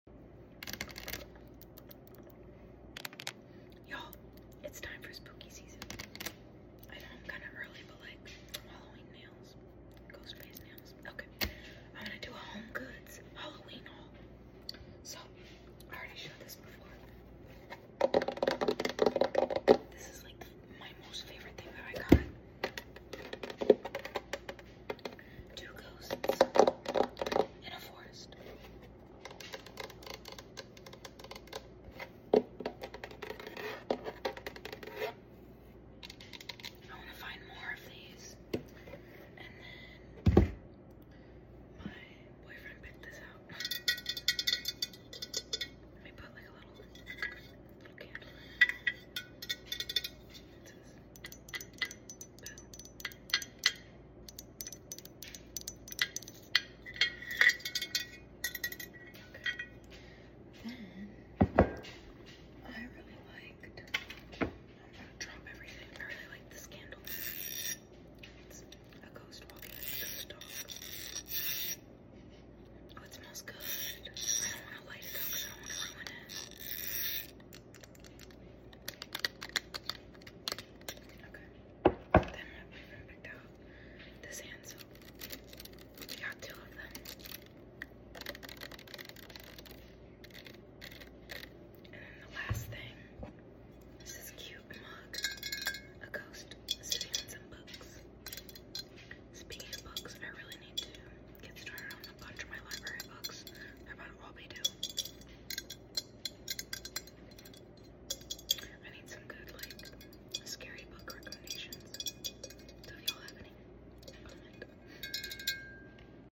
ASMR Home Goods Spooky Haul Sound Effects Free Download